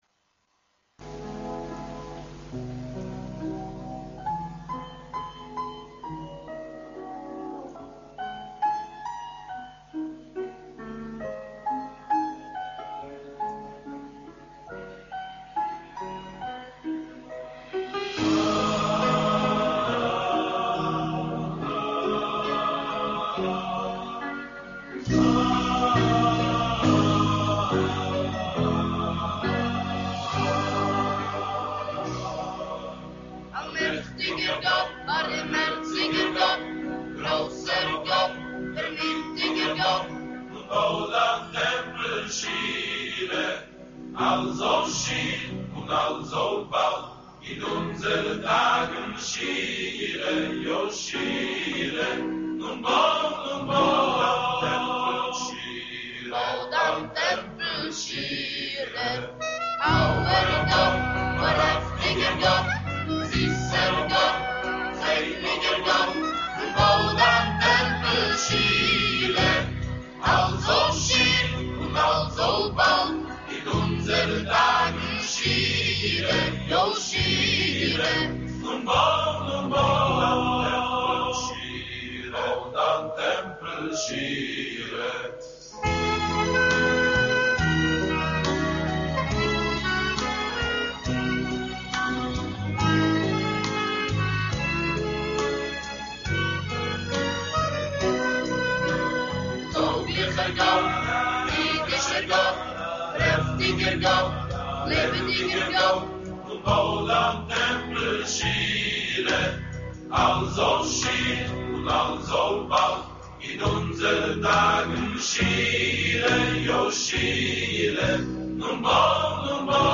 vocal and instrumental